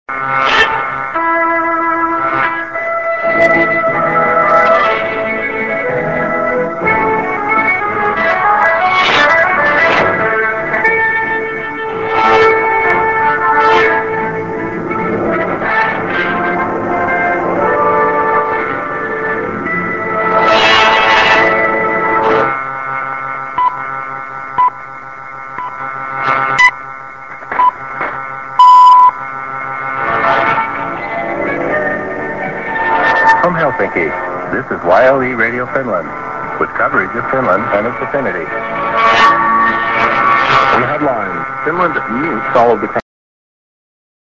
St. IS->TS->ID(man)